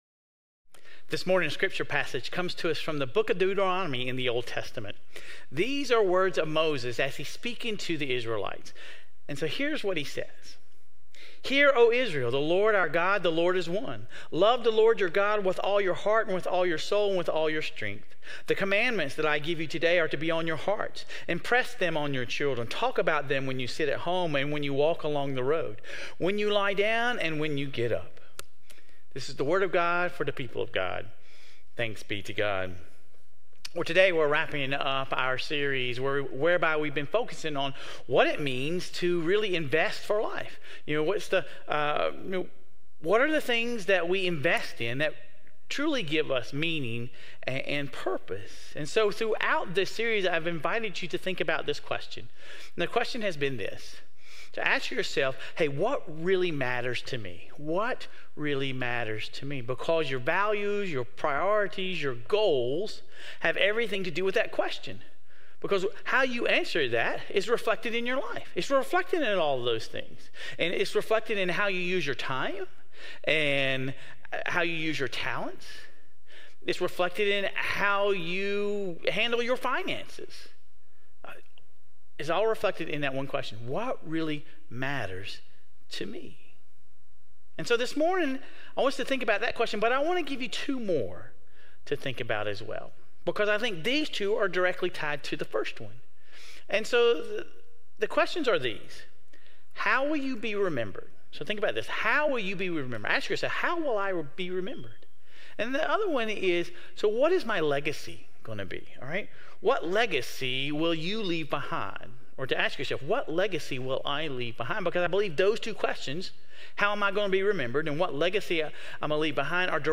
During this worship, we continued our worship series in coordination with our 2024 stewardship campaign: Investing for Life.